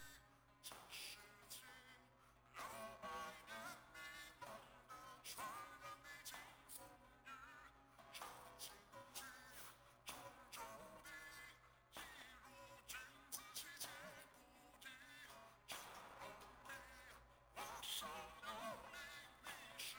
There are three pcmd3140 chips on my device, each connected to two microphones. everyone The audio collected is distorted, and I have no idea what is causing this issue.